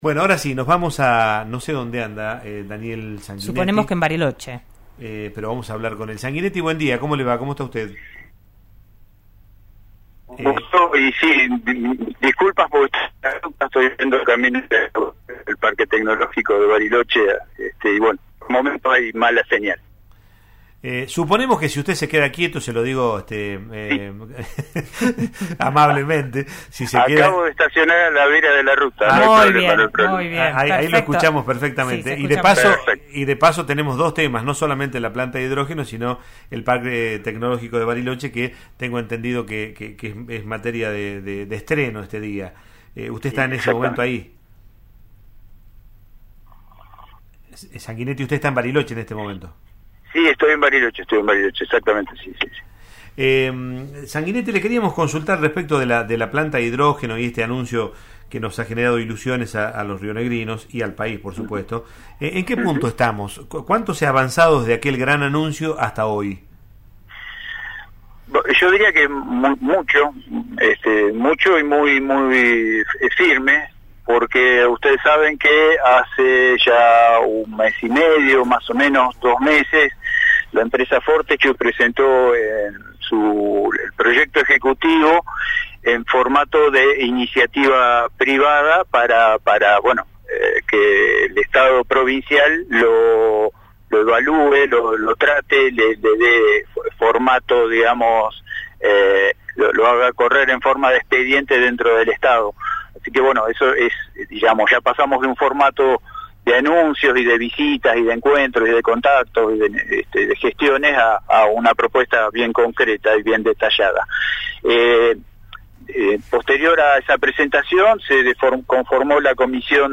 En diálogo con RN Radio, el funcionario explicó que el total de las etapas, que son 5 y que tienen un desarrollo de dos años aproximadamente cada una, terminarán generando «unos 16.500» empleos, pero bajó las expectativas inmediatas porque se trata de ir generando demanda de mano de obra a medida que se avanza con cada uno de los procesos anunciados.